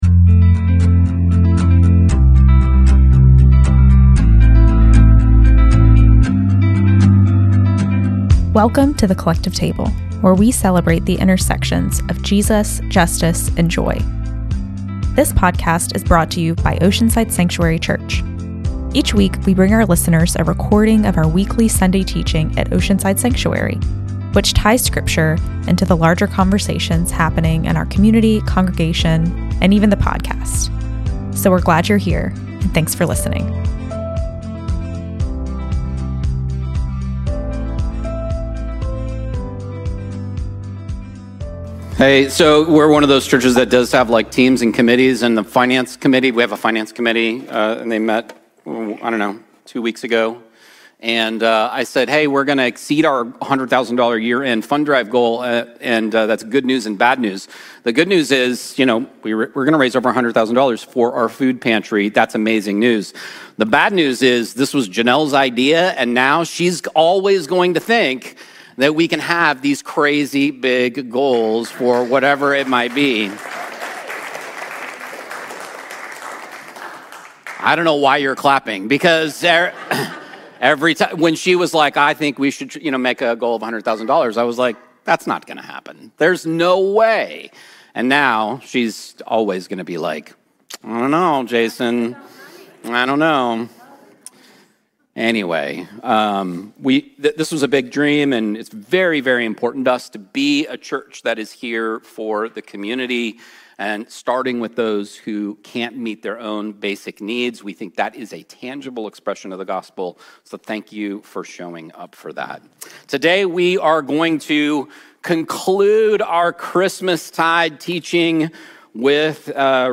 A collection of teachings from our Sunday gathering and classes … continue reading 100 episodes # Society # Religion # Christianity # Podcasting Education # Self-Improvement # Philosophy #